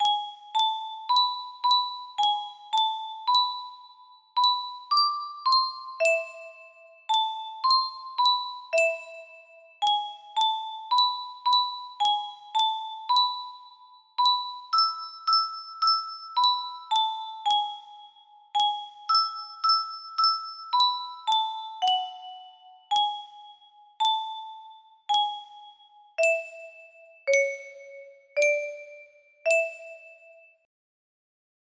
Yay! It looks like this melody can be played offline on a 30 note paper strip music box!